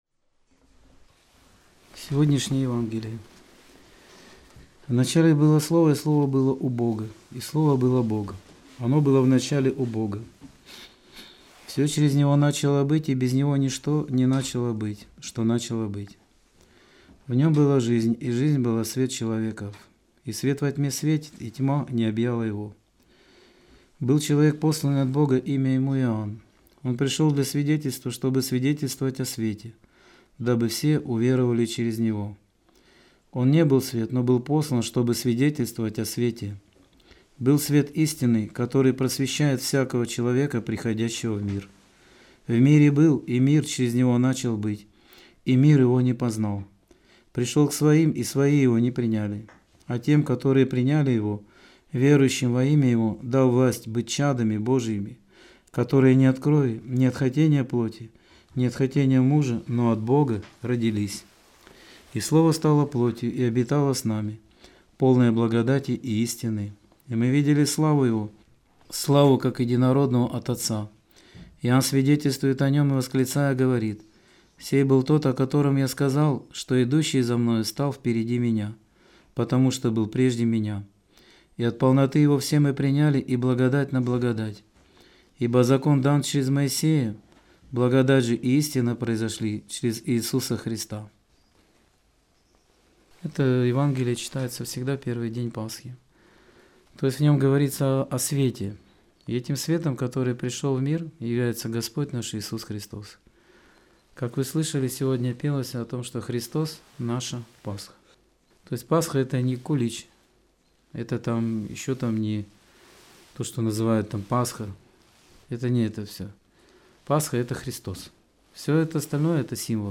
Аудио-проповедь 28.04.2019